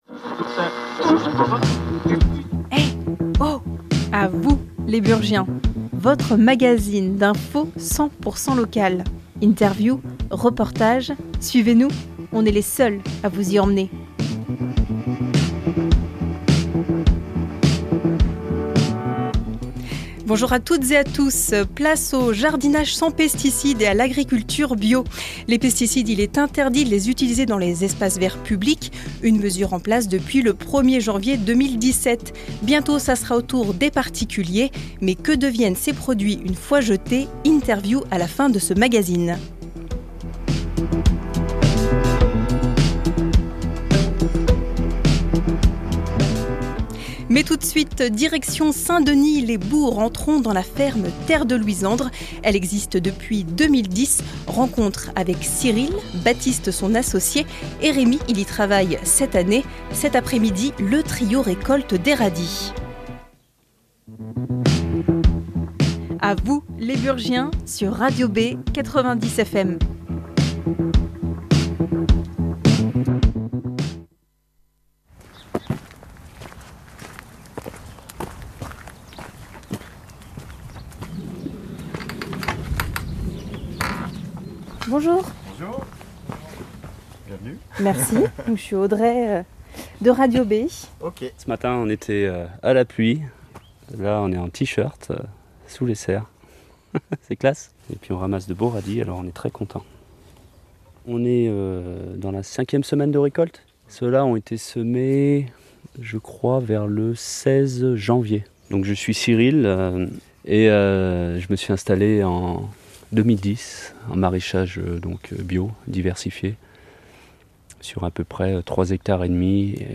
Rencontre pendant la récolte des radis. Les pesticides sont interdits depuis 2017 dans les espaces verts publics.